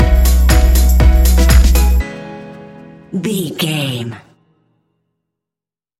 Ionian/Major
house
electro dance
synths
techno
trance